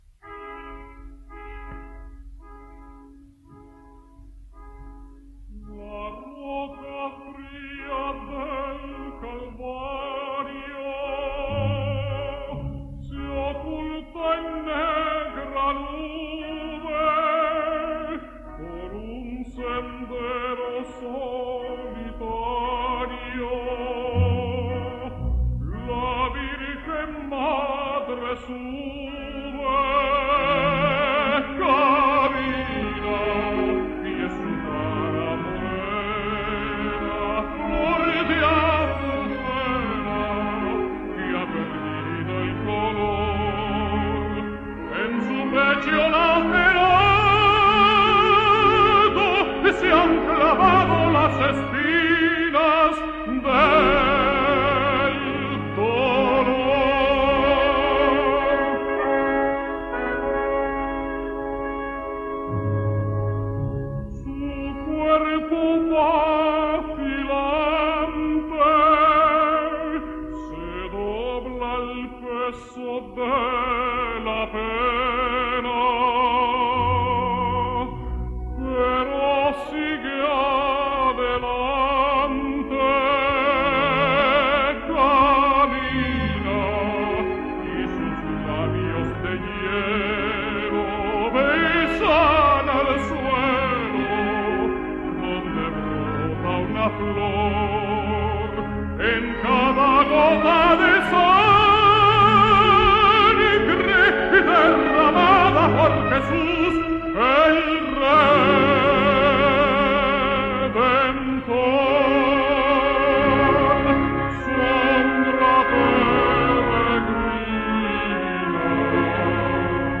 Vanzo has one of those voices that are just made for the many wonderful duets, that are so frequent in French and Italian opera.